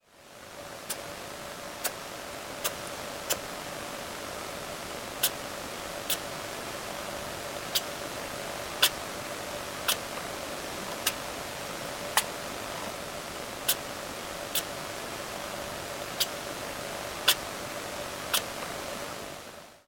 While grooming, sugar gliders will make little light sneezes one after the other.
Grooming sneeze1
sneezing2.wav